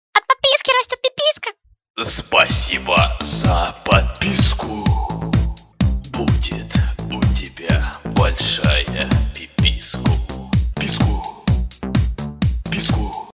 Звуки для подписки